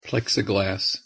“Plexiglass” از Pronunciation in English توسط Forvo.
pronunciation_en_plexiglass.mp3